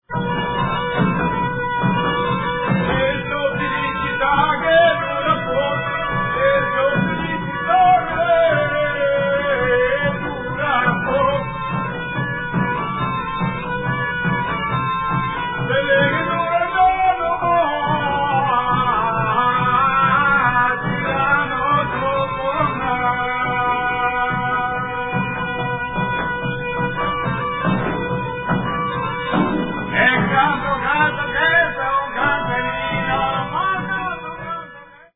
now digitally remastered